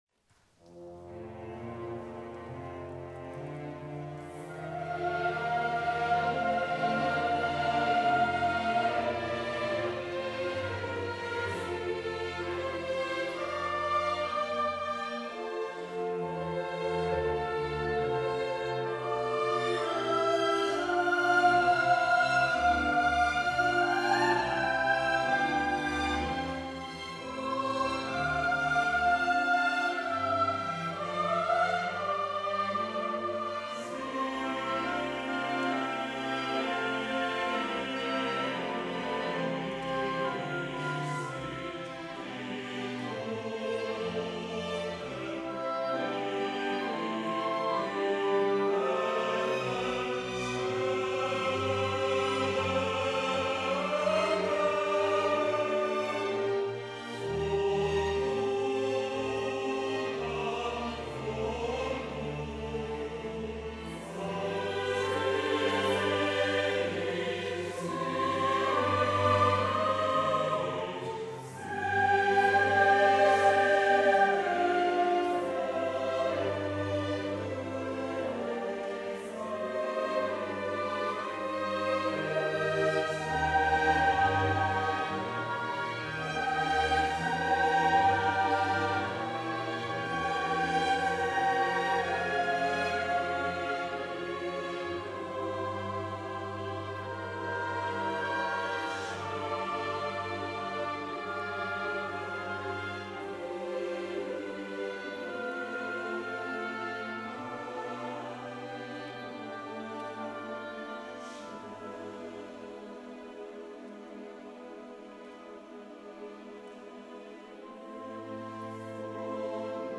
A szoprán után a basszus is elénekli a témát, majd rövid kórus kidolgozás következik.